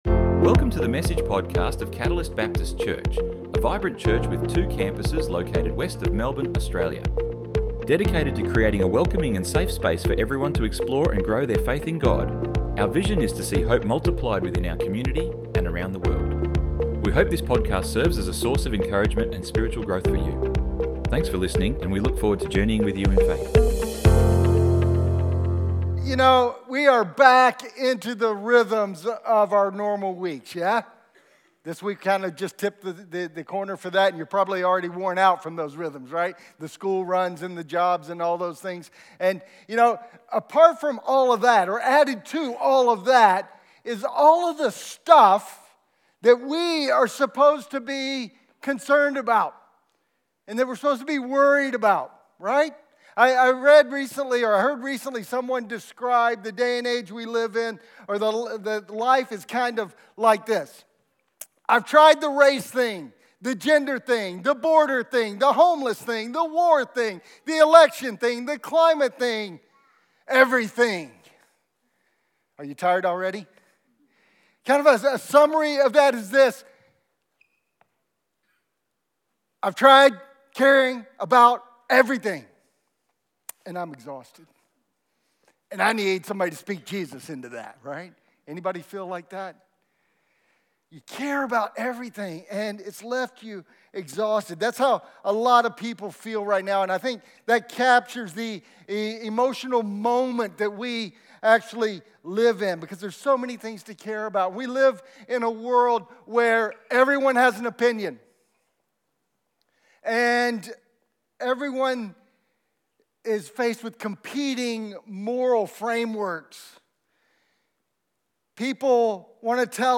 Download Download Sermon Notes Bless-This-Mess-Wk-1.pdf 01 - online notes - Bless this Mess - Why Trying Harder Isn’t Working.docx Exhausted by pressure, opinions, and trying to get it right?